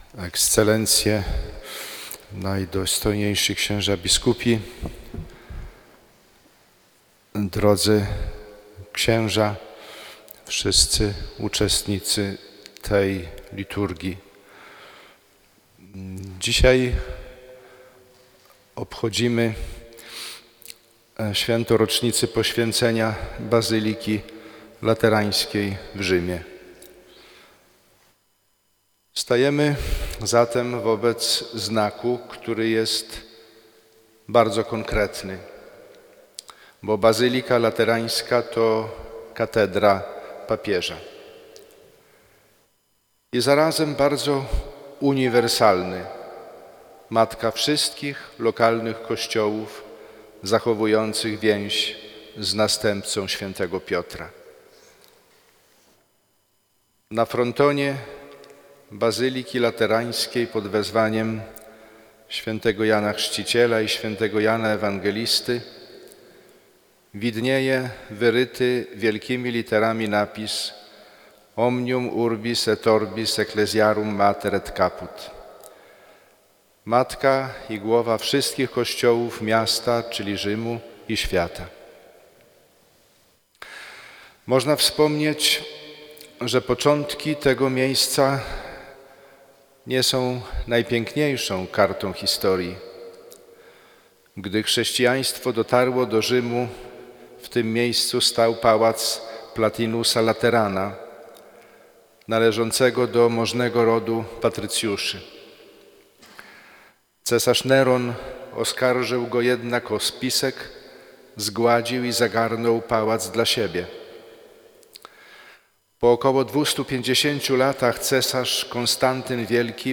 Biskup Piotr Sawczuk 9 listopada 2024 r. w drohiczyńskiej katedrze przewodniczył Mszy św. w intencji zmarłych biskupów, rządców oraz kapłanów diecezji drohiczyńskiej i jej poprzedniczek.
Posłuchaj homilii bp. Piotra Sawczuka: